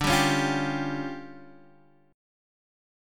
DmM7bb5 chord